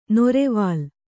♪ norevālu